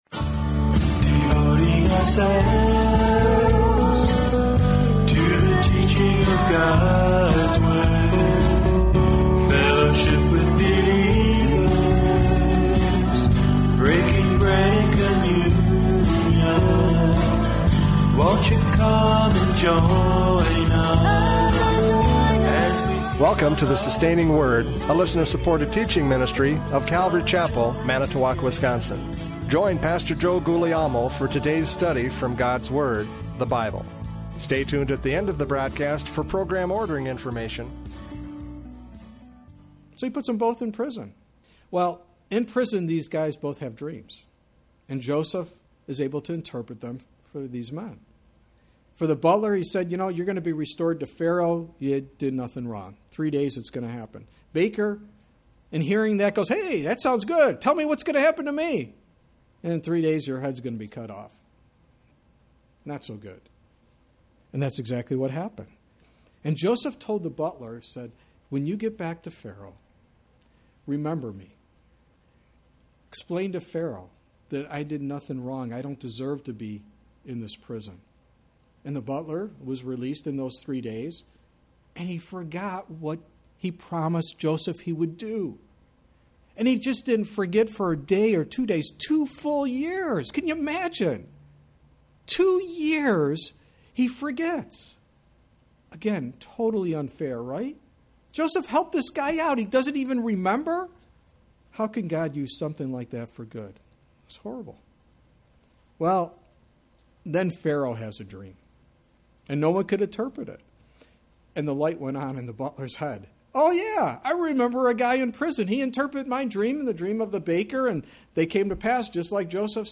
Judges 11:1-11 Service Type: Radio Programs « Judges 11:1-11 Love Shines Through!